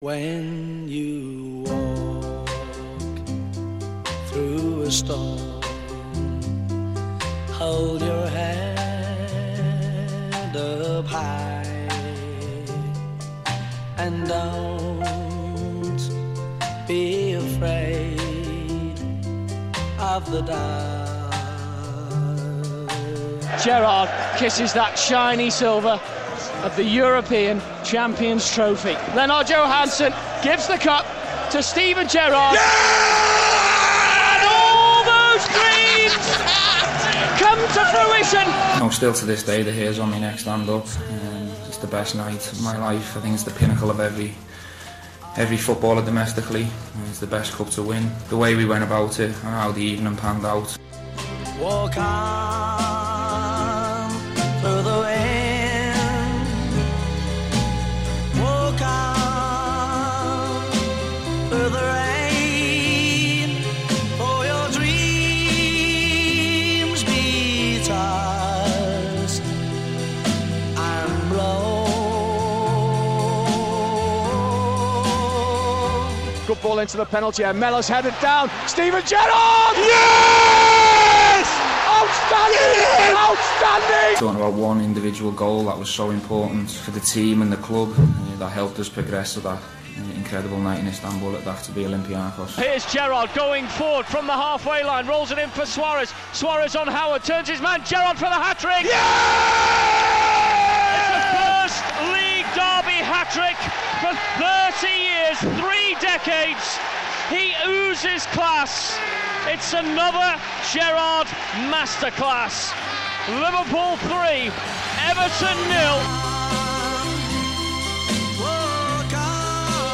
Liverpool captain recounts some of his greatest memories ahead of his final game at Anfield